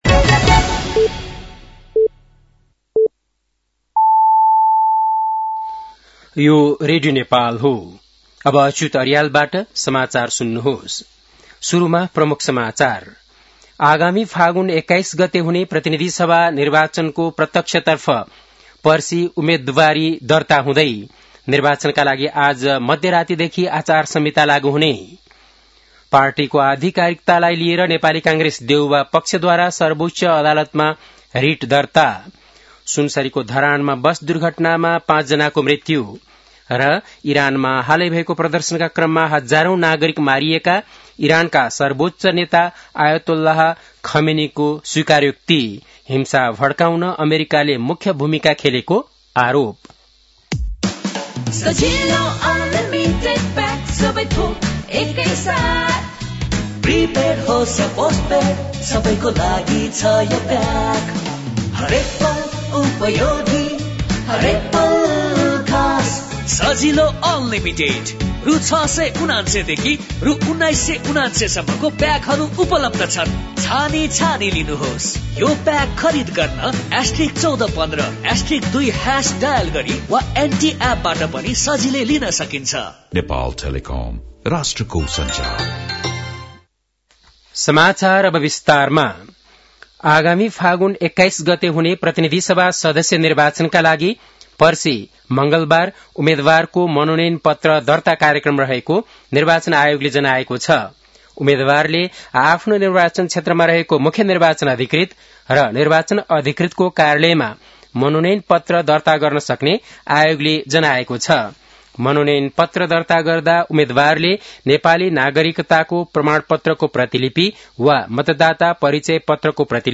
बेलुकी ७ बजेको नेपाली समाचार : ४ माघ , २०८२
7-pm-nepali-news-10-04.mp3